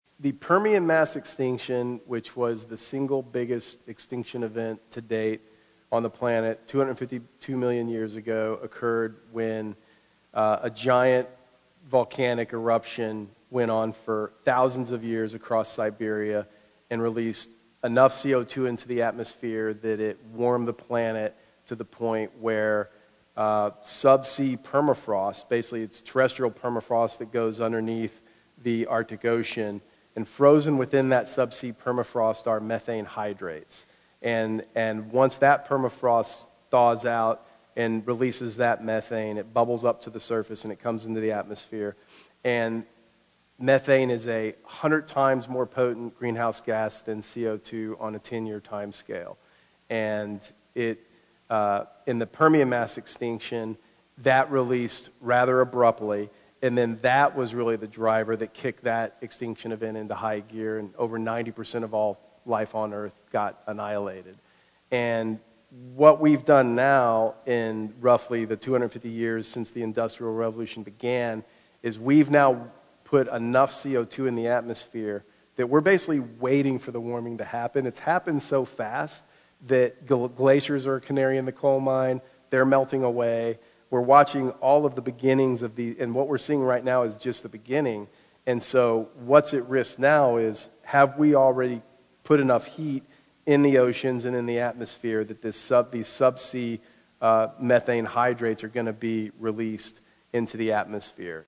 Special podcast, part 2: Dahr Jamail Interviewed About ‘The End of Ice’